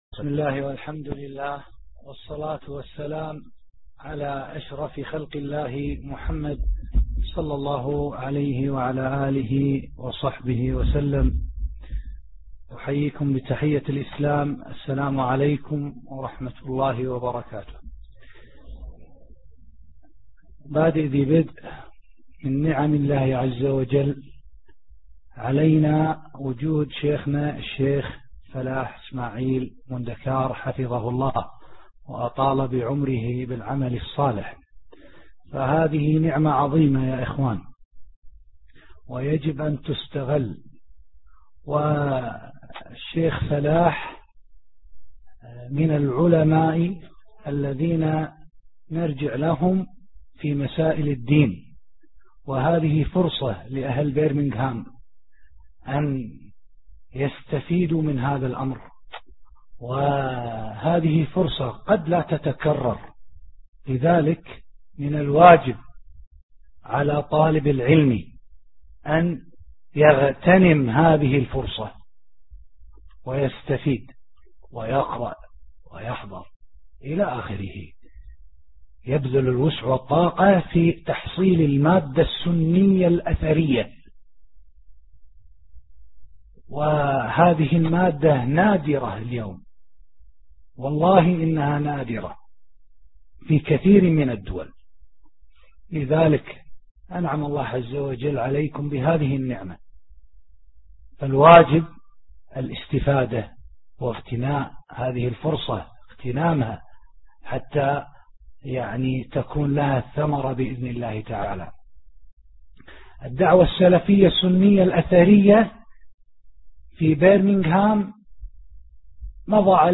محاضرة عام 2008
القسم: الدروس المنقولة عبر إذاعة النهج الواضح القسم العلمي: العقيدة والمنهج